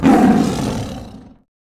CosmicRageSounds / wav / general / combat / creatures / tiger / she / turn1.wav